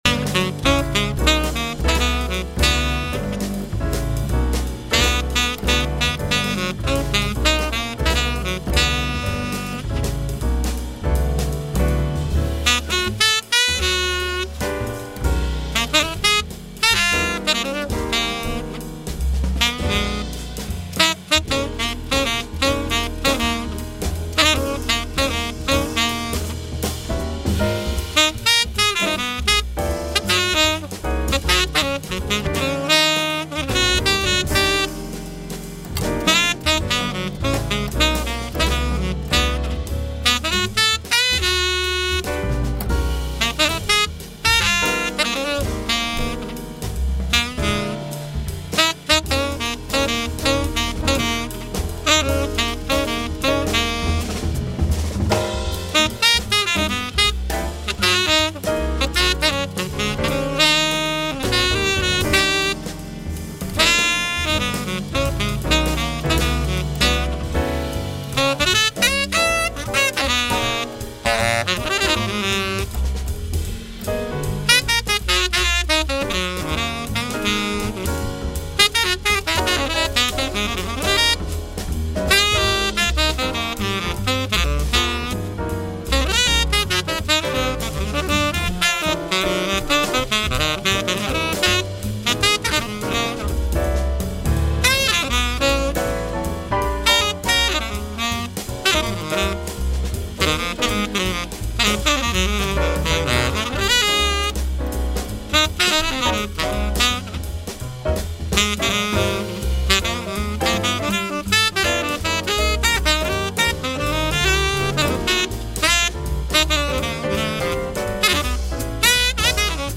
This site provides play-tests of various saxophone models
Saxophone Play-Tests